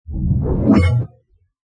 Stealth, Spy, Game Menu, Ui Clear Sound Effect Download | Gfx Sounds
Stealth-spy-game-menu-ui-clear.mp3